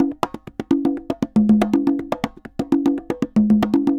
Congas_Salsa 120_6.wav